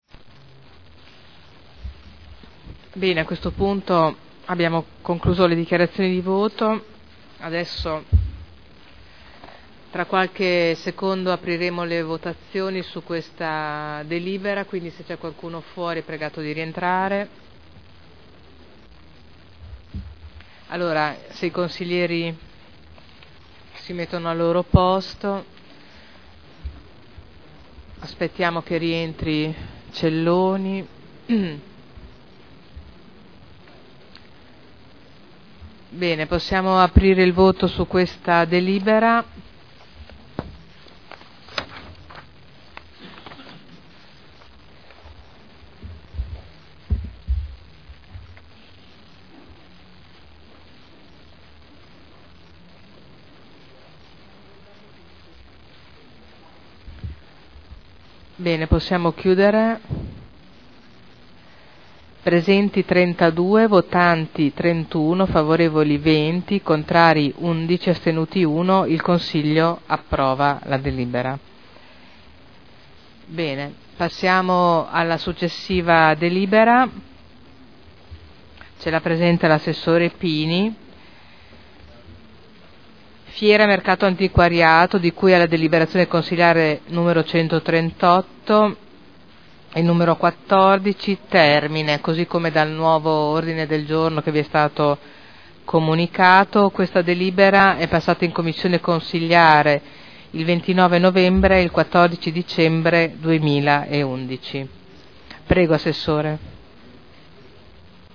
Seduta del 22/12/2011. Mette ai voti proposta di deliberazione.